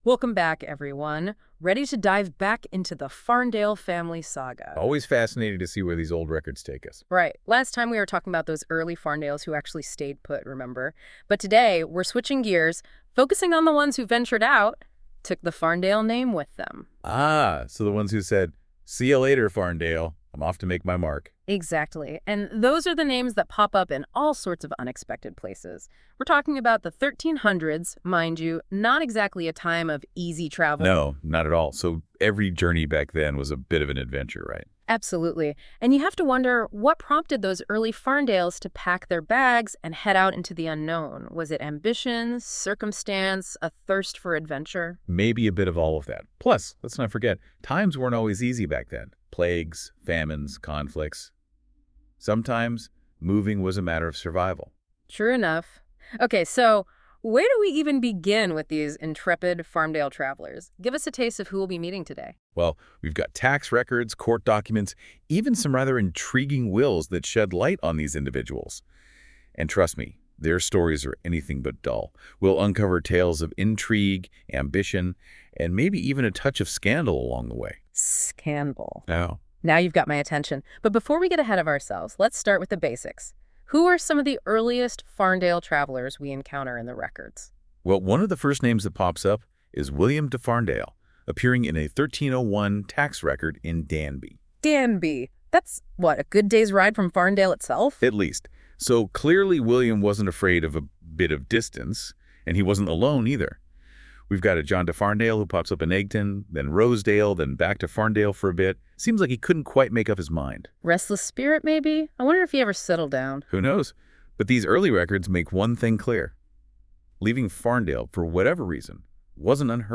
Using Google�s Notebook LM, listen to an AI powered podcast summarising this page. This should only be treated as an introduction, and the AI generation sometimes gets the nuance a bit wrong.